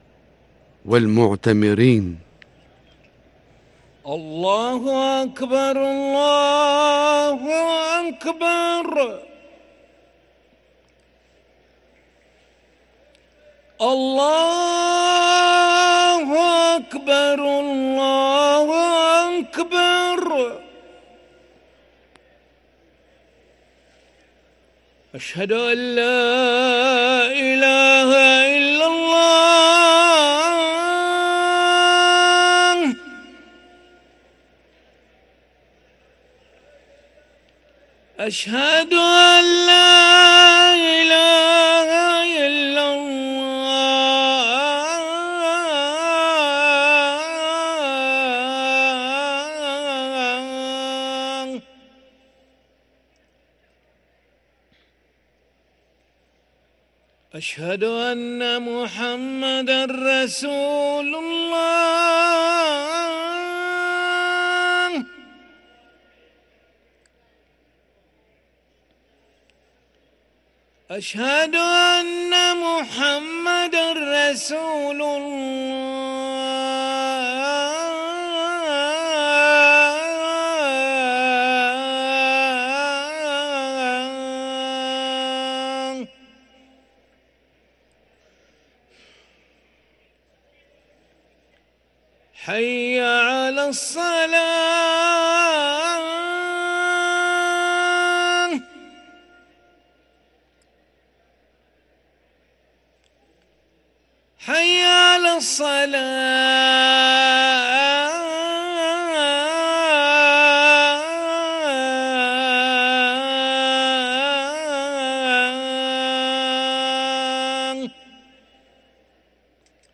أذان العشاء للمؤذن علي ملا الأحد 11 رمضان 1444هـ > ١٤٤٤ 🕋 > ركن الأذان 🕋 > المزيد - تلاوات الحرمين